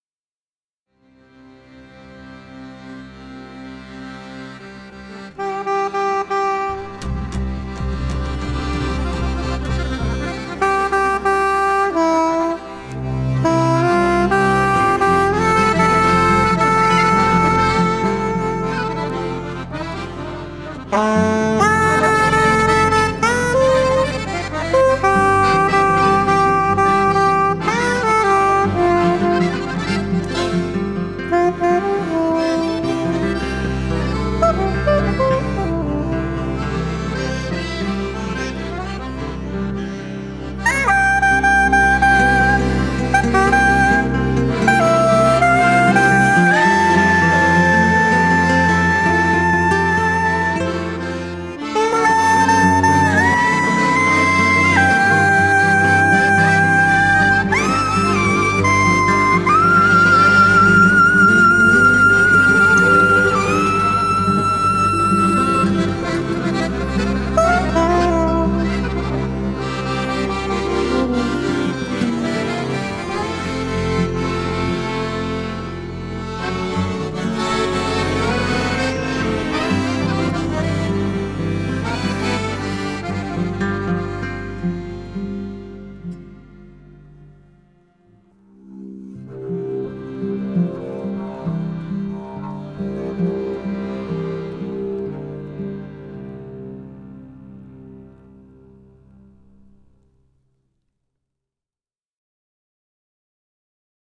Musica per le immagini